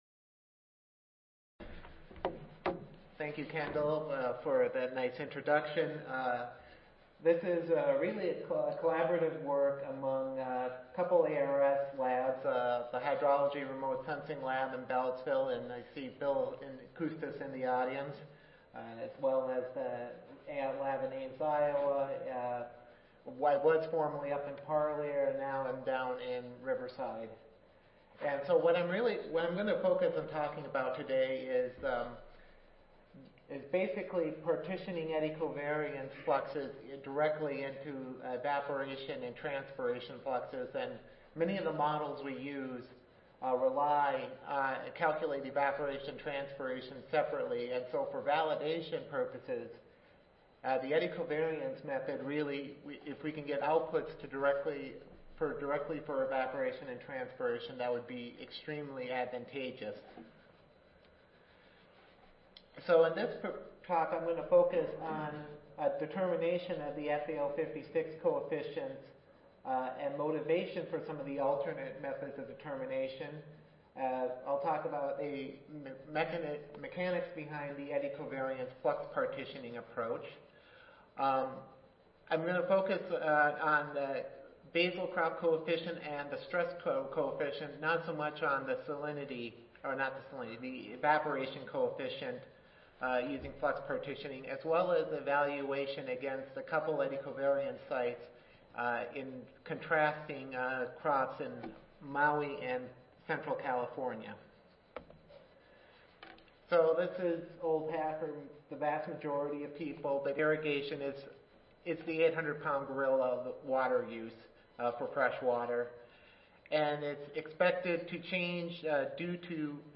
Session: Symposium--Model Simulation Comparisons with Experimental Observations of Evapotranspiration (ASA, CSSA and SSSA International Annual Meetings (2015))
Recorded Presentation